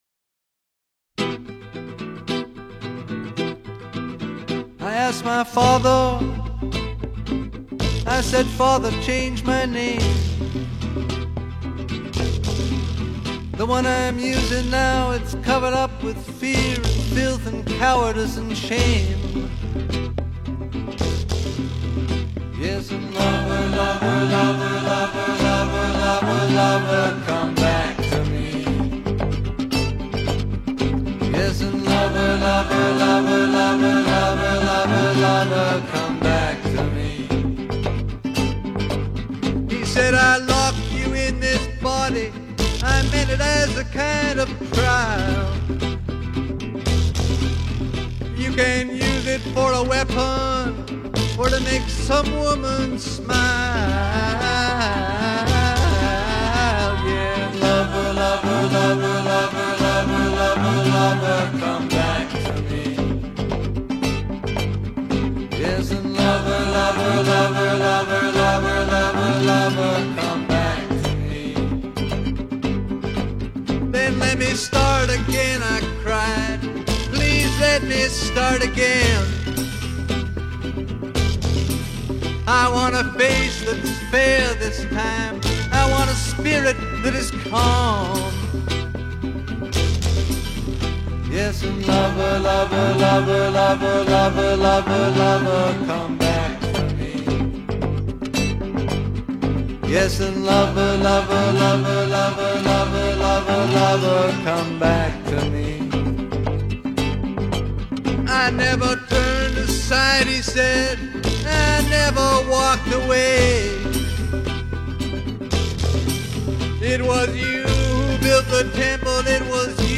آلبوم زنده فولک
Folk Rock, Singer-Songwriter